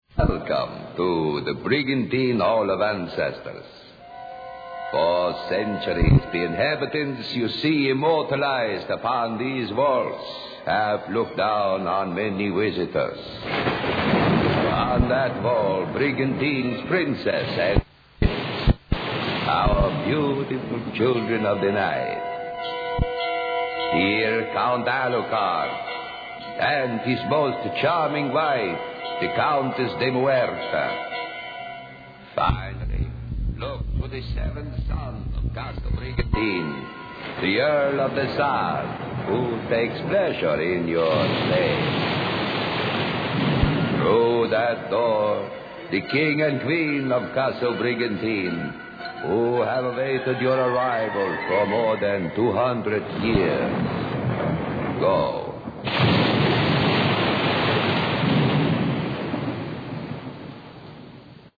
Part of what made Brigantine Castle so unique was the quality of sound effects that could be heard throughout the castle.  High production values were used when creating these eerie sound tracks.